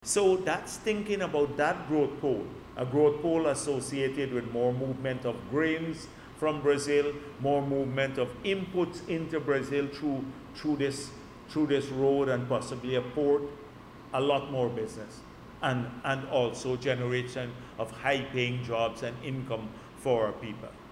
During a recent press conference, Vice President Dr. Bharrat Jagdeo emphasized the pivotal role that northern Brazil will play in driving Guyana’s ongoing development and prosperity.